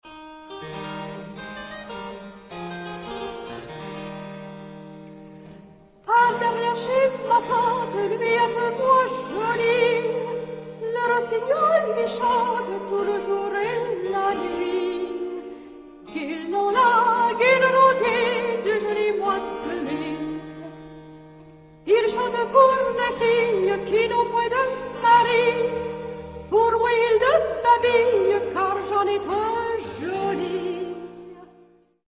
flûte, piccolo baroque, flûte à bec
violon baroque
violoncelle baroque
clavecin
percussions, tambour militaire